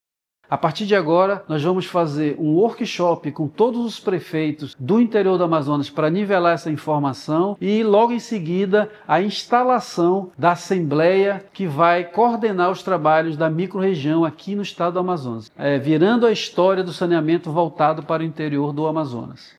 O secretário da Sedurb, Marcellus Campêlo, sinaliza os próximos passos após as mudanças.